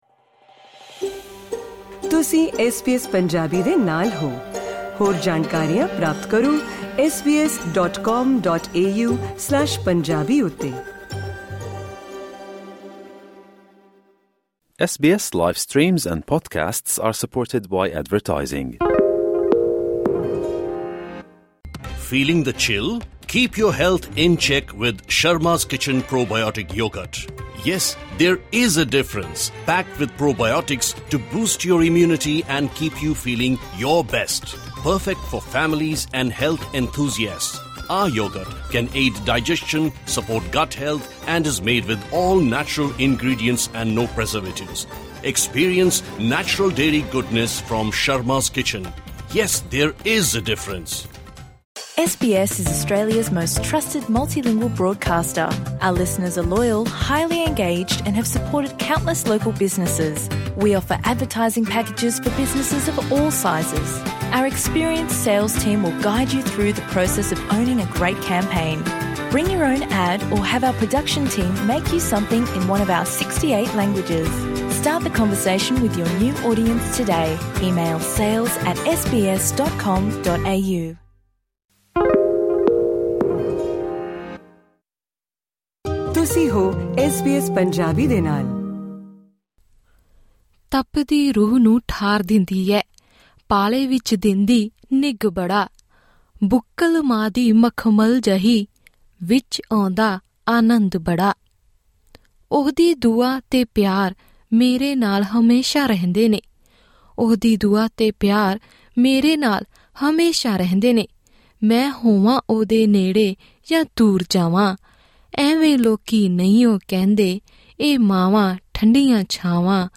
ਇਹ ਦਿਵਸ ਮਾਵਾਂ ਦੇ ਪਿਆਰ, ਸਮਰਪਣ, ਮਮਤਾ ਅਤੇ ਕੁਰਬਾਨੀਆਂ ਨੂੰ ਸਮਰਪਿਤ ਹੈ ਅਤੇ ਹਰ ਸਾਲ ਦੀ ਤਰ੍ਹਾਂ ਇਸ ਸਾਲ ਵੀ ਆਸਟ੍ਰੇਲੀਆ ਵਿੱਚ ਇਸਨੂੰ ਲੈਕੇ ਕਾਫੀ ਉਤਸ਼ਾਹ ਦੇਖਣ ਨੂੰ ਮਿਲ ਰਿਹਾ ਹੈ। ਐਸ ਬੀ ਐਸ ਪੰਜਾਬੀ ਨਾਲ ਕੁੱਝ ਮਾਵਾਂ ਨੇ ਗੱਲਬਾਤ ਕੀਤੀ ਅਤੇ ਮਾਂ ਬਨਣ ਦੇ ਮਾਇਨੇ ਬਿਆਨ ਕਰਦਿਆਂ ਆਪਣੇ ਤਜ਼ੁਰਬੇ ਸਾਂਝੇ ਕੀਤੇ।
ਆਸਟ੍ਰੇਲੀਆ ਵੱਸਦੀਆਂ ਇਨ੍ਹਾਂ ਮਾਵਾਂ ਦੇ ਮਾਂ ਦਿਵਸ ਪ੍ਰਤੀ ਵਿਚਾਰ ਜਾਨਣ ਲਈ ਇਹ ਰਿਪੋਰਟ ਸੁਣੋ...